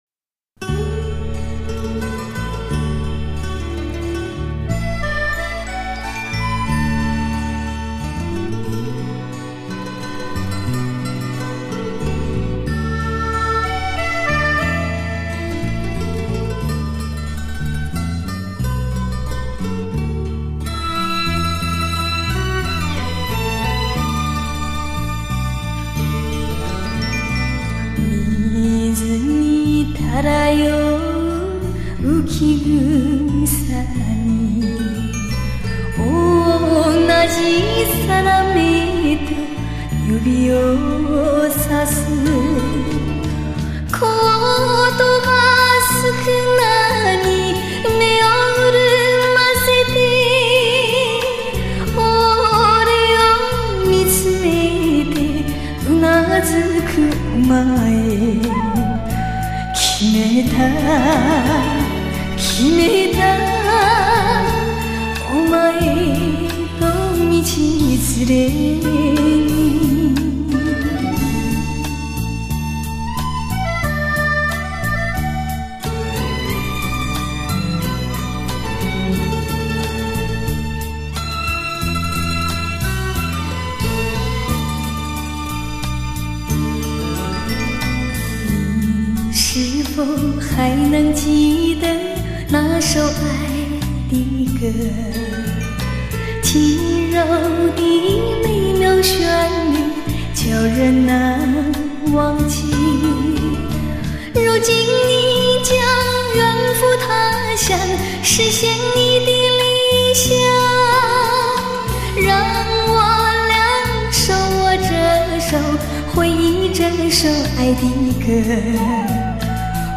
用她最甜美的嗓音征服海外听眾